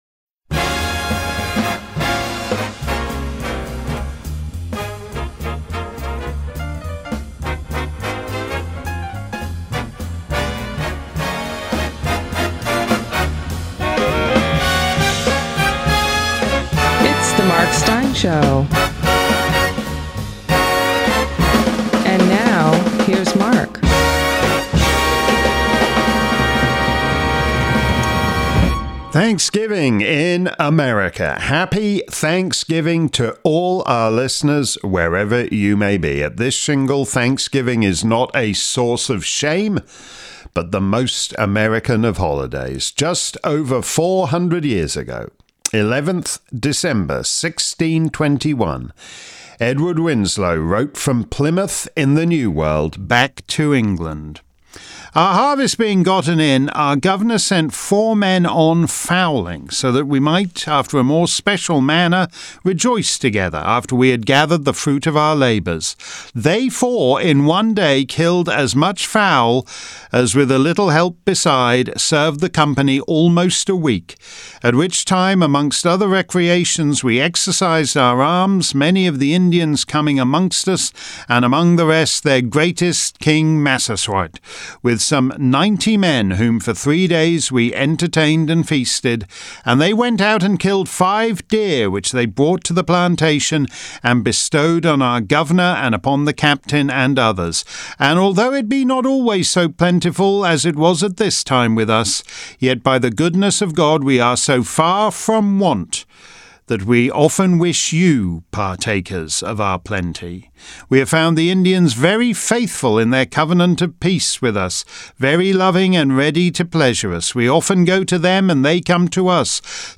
We wouldn't dream of not doing the annual Thanksgiving Day broadcast of The Mark Steyn Show .